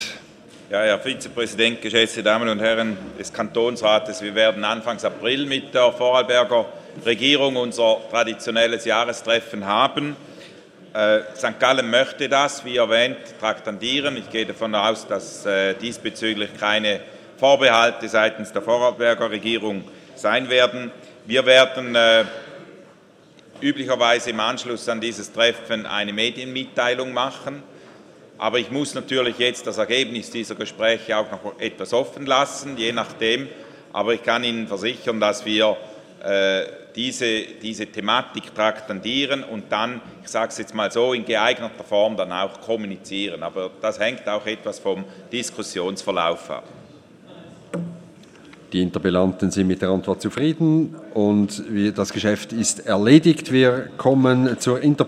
29.2.2016Wortmeldung
Session des Kantonsrates vom 29. Februar bis 2. März 2016, ausserordentliche Session vom 3. März 2016